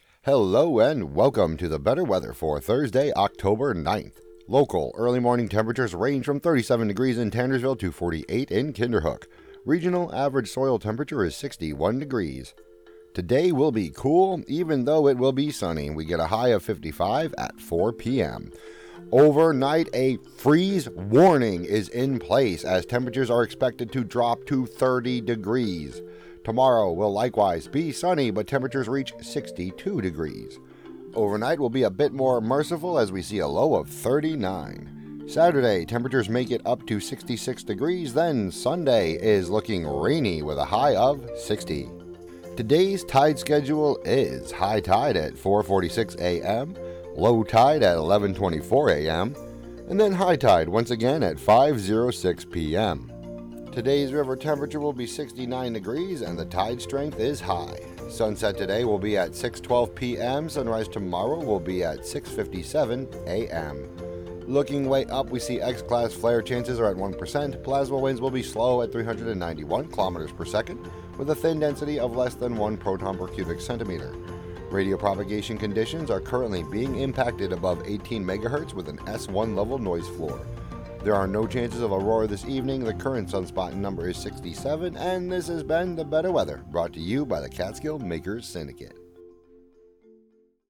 and more on WGXC 90.7-FM.